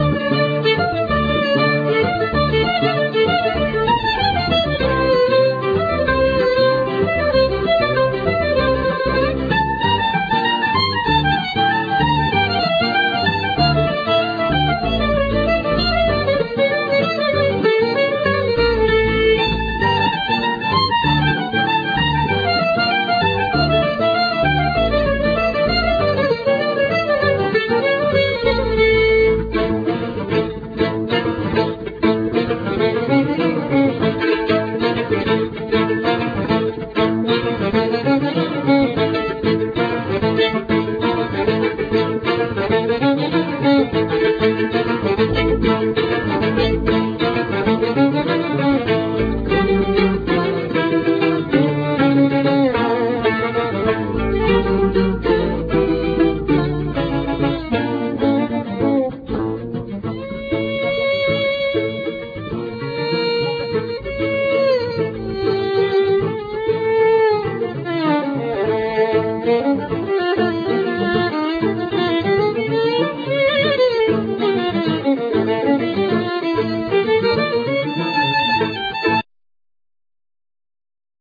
Vocal
Violin
Mandolin,Koncovka
Double-bass
Gitar
Clarinet,Tarogato
Banjo,Low-whistle,Tarogato